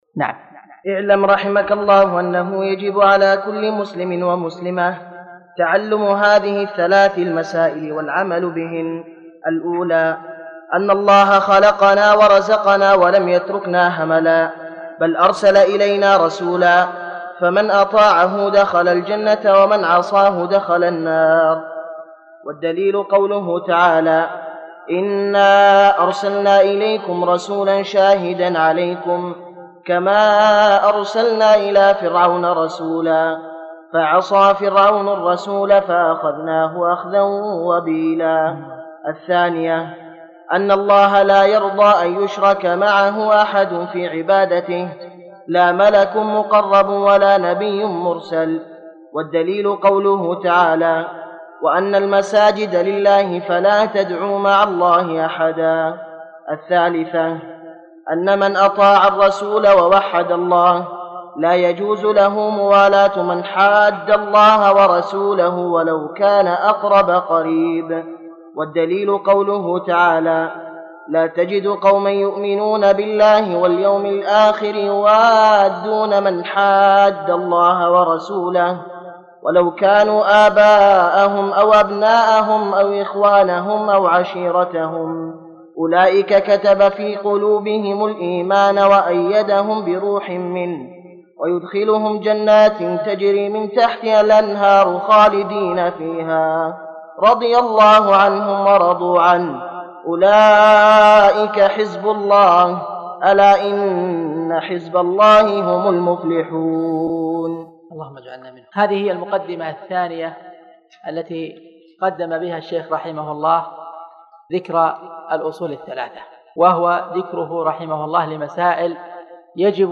الدرس(2) لا يرضى الله لعباده الشرك